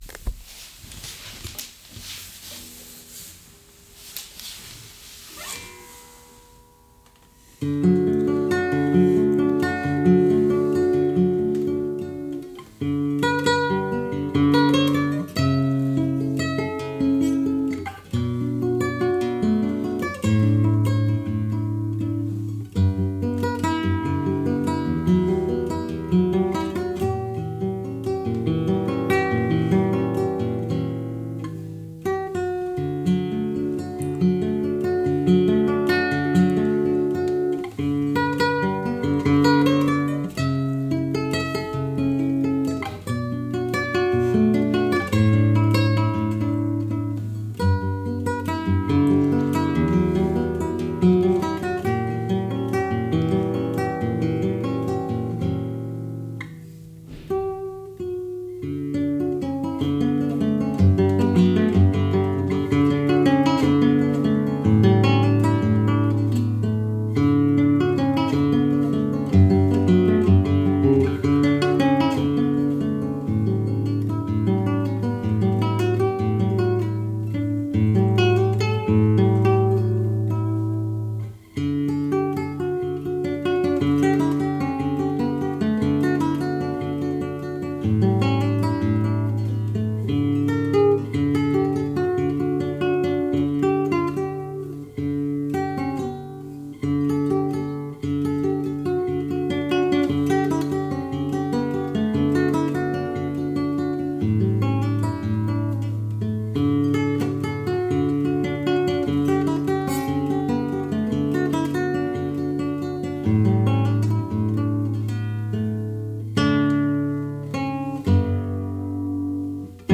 Konzertgitarre: Zoom H6 klingt auf dem Gerät gut, aber dann Probleme mit der Soundqualität (Reader etc.)
Die Eigenkompositionen werde ich auf selbstgebauten Gitarren einspielen - das ist zumindest das Ziel (hier ein Bild...
Zunächst finde ich, dass es nach mehr Entfernung von der Gitarre als 30 cm klingt - es ist doch recht viel vom (zu kleinen) Raum zu hören.
Dann ist die Färbung der internen Mikros zu hören - das ist ähnlich wie beim H5.
Dann ist auf der Aufnahme deutliches Rauschen zu hören, dass bei einer Bearbeitung noch weiter nach vorne kommt - da geraten die internen Vorverstärker im H6 dann doch an ihre Grenze.